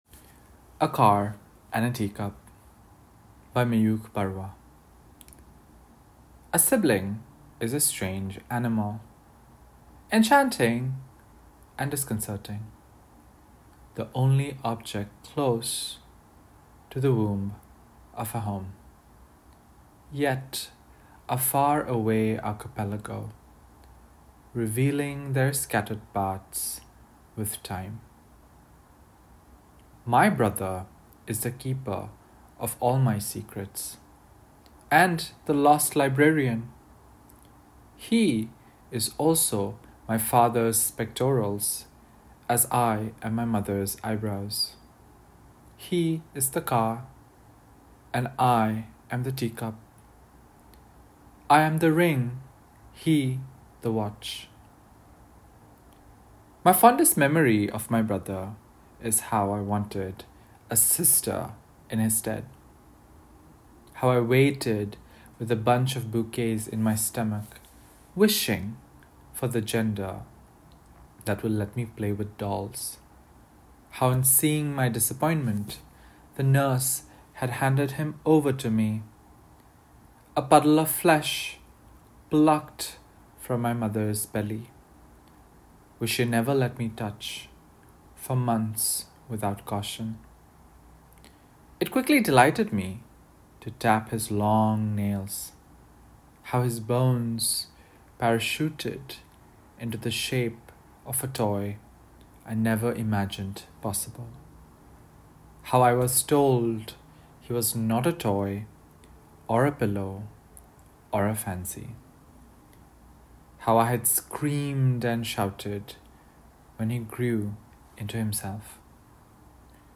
A-Car-and-a-Teacup-without-music.mp3